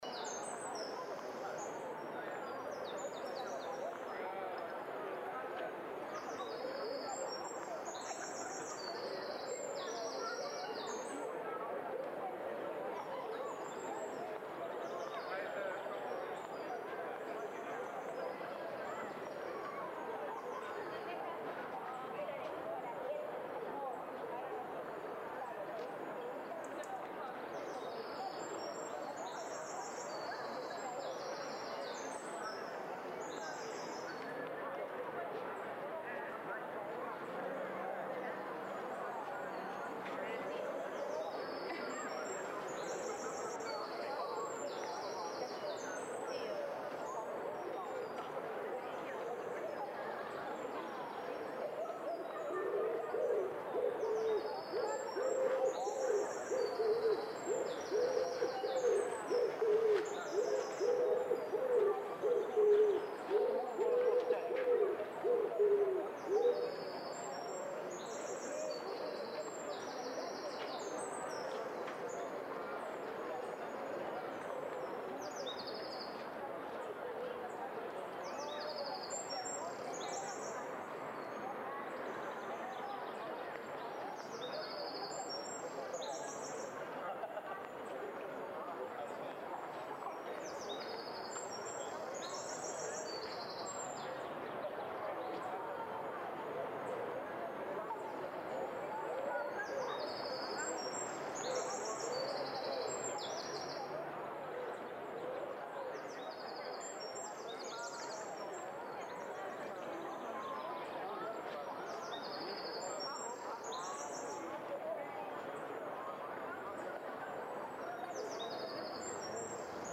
Garden with People.ogg